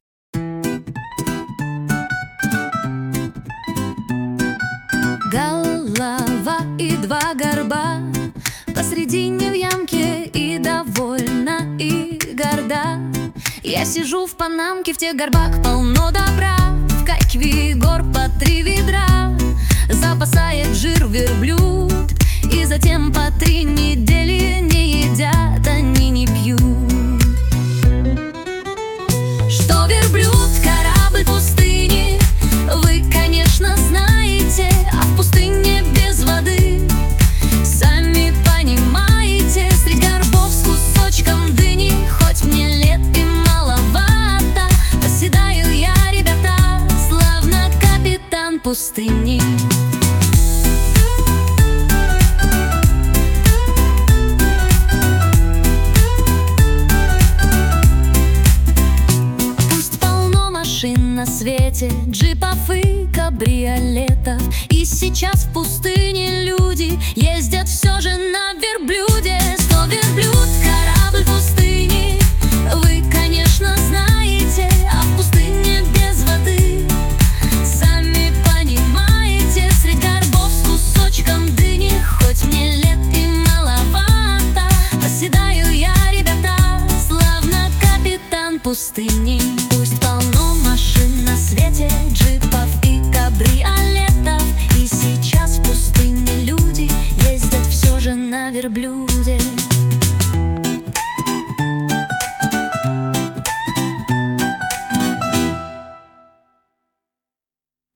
• Аранжировка: Ai
• Жанр: Детская